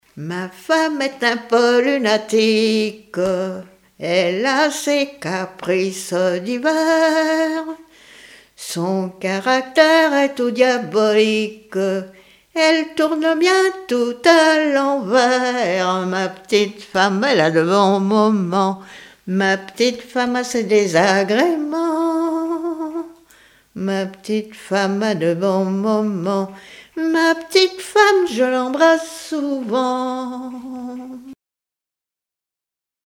Genre strophique
Témoignages, chansons de variété et traditionnelles
Pièce musicale inédite